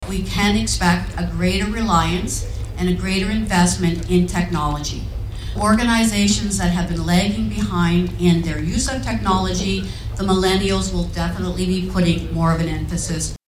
At Belleville’s Ramada Hotel, they welcomed the Municipality of Brighton to the event, which focused on thanking the business community, and talked about the changing workplace.